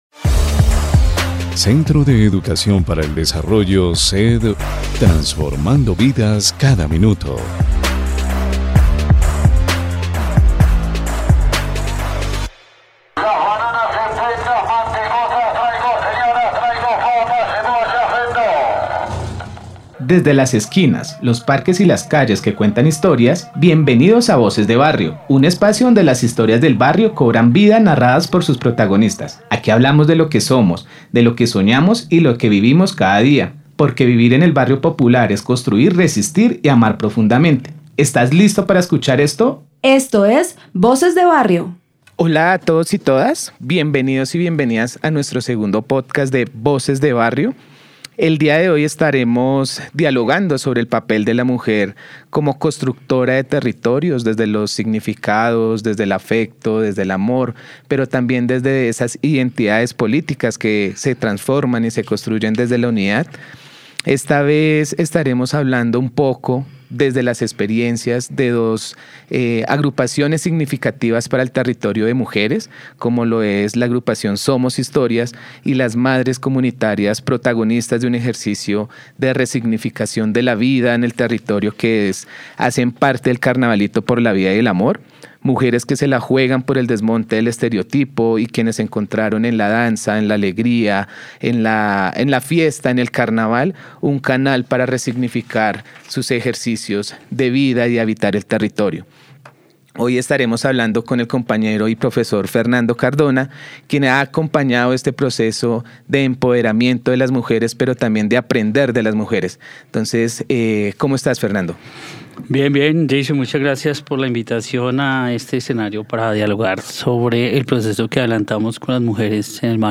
Durante la emisión, tendremos la oportunidad de conversar con dos destacadas docentes universitarias y expertas en procesos comunitarios